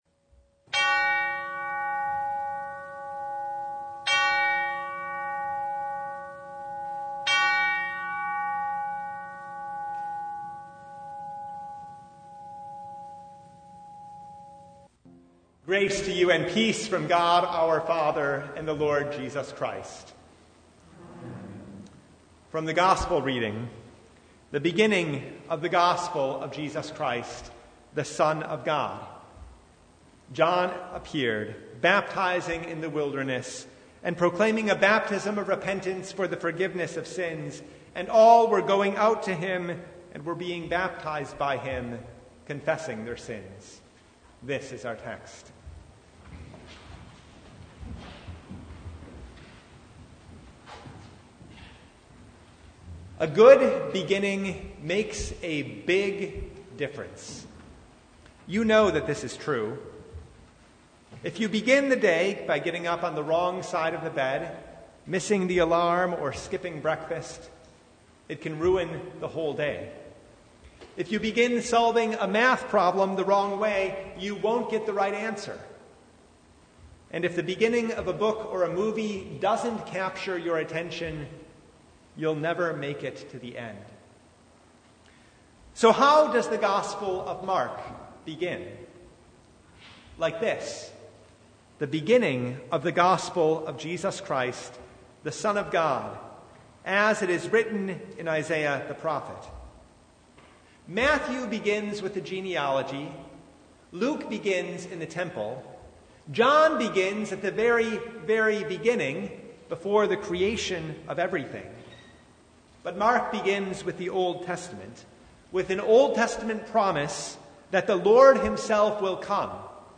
Mark 1:1-8 Service Type: Advent The way of the Lord begins with repentance and faith.